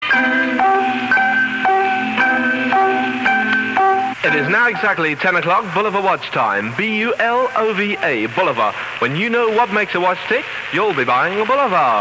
© 2014- 2024 Offshore Radio Museum Home Basement Ground Floor 1 Floor 2 Many of the commercials aired by Radio Caroline were recorded either in the basement studios of Caroline House, 6 Chesterfield Gardens or by advertising agencies on behalf of their clients.
However there were also commercials which were read out ‘live’ by the on air DJ, giving an immediacy and urgency to the advertisers’ message.
Bulova Watch advert 2.mp3